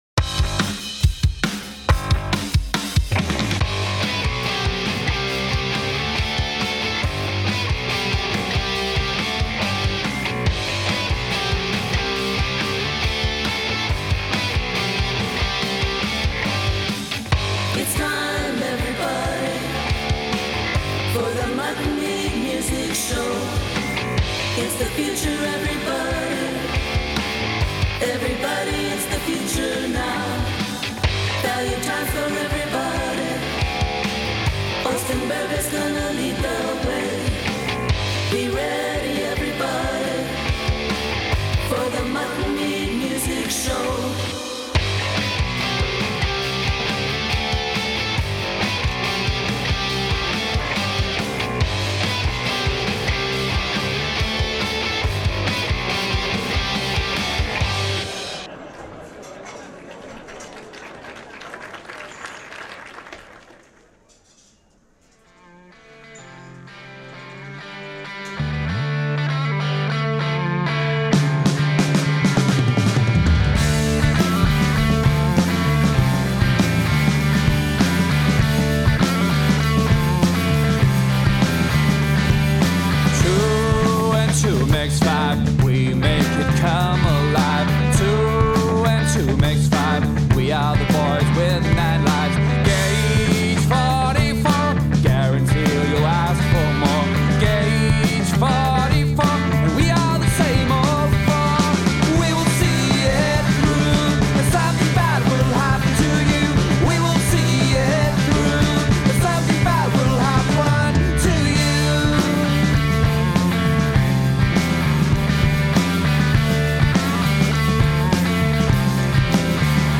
Piano Special volume 2